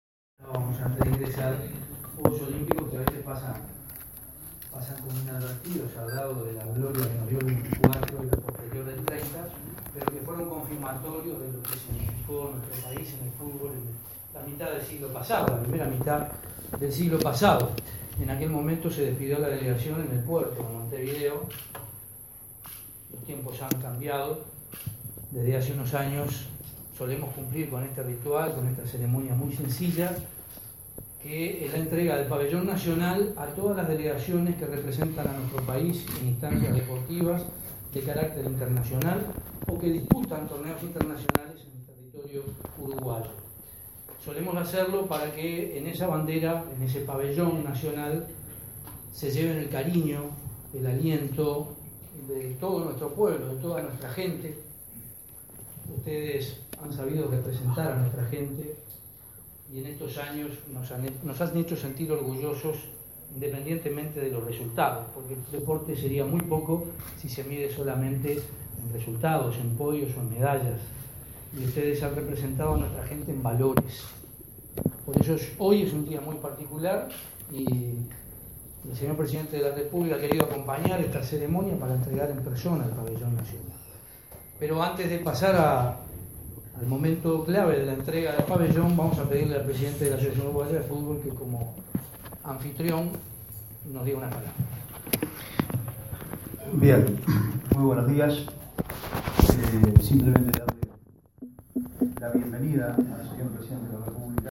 El presidente Tabaré Vázquez, acompañado por el secretario nacional del Deporte, Fernando Cáceres, entregó el Pabellón Nacional a la selección de fútbol que disputará el mundial de Rusia.